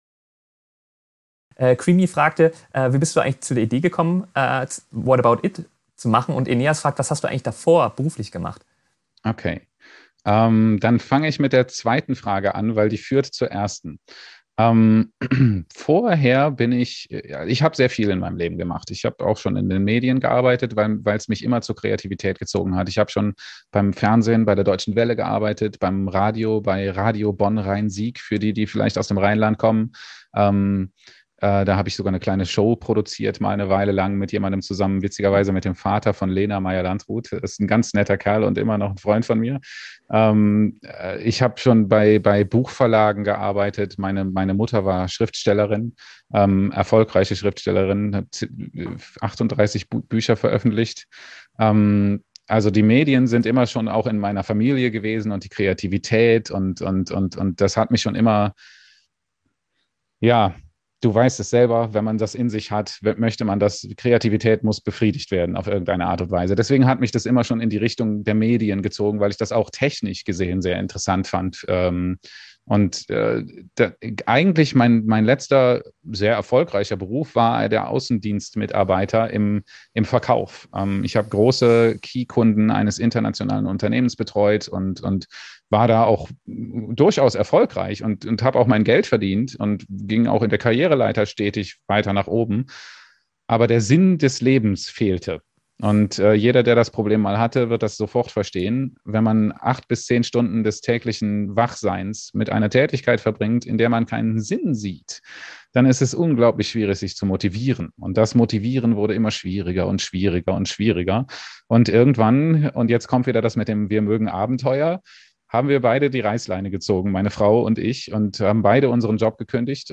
Ich hab den Livestream geschnitten und mit Untertiteln versehen.